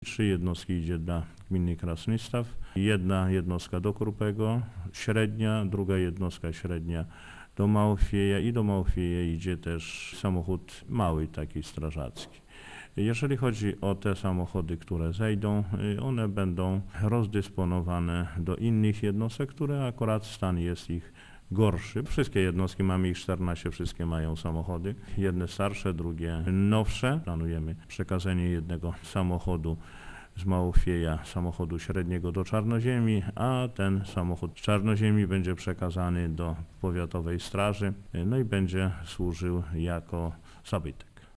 - W sumie kupujemy sześć samochodów pożarniczych, w tym cztery średnie i dwa lekkie - mówi Informacyjnej Agencji Samorządowej Janusz Korczyński, wójt gminy Krasnystaw, która jest liderem projektu: